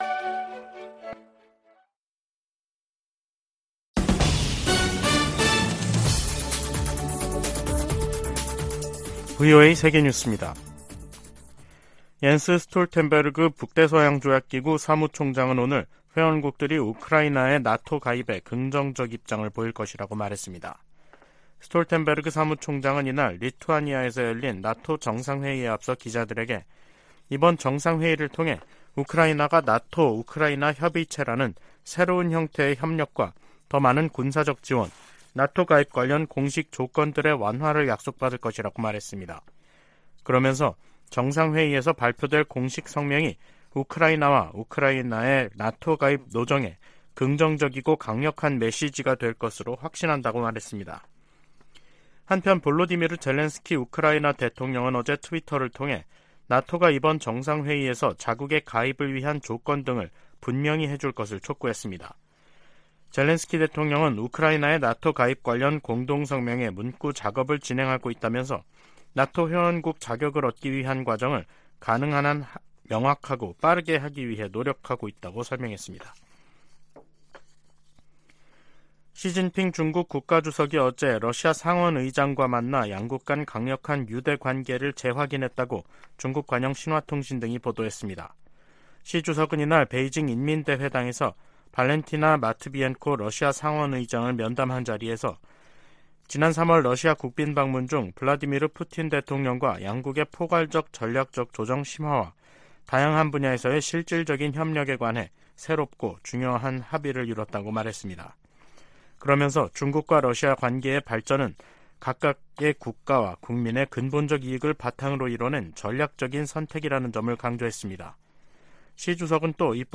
VOA 한국어 간판 뉴스 프로그램 '뉴스 투데이', 2023년 7월 11일 2부 방송입니다. 북한 김여정 노동당 부부장이 이틀 연속 미군 정찰기의 자국 상공 침범을 주장하며 군사적 대응을 시사하는 담화를 발표했습니다. 이와 관련해 미 국무부는 북한에 긴장 고조 행동 자제를 촉구했습니다. 미한 핵 협의그룹 NCG 첫 회의가 다음 주 서울에서 열립니다.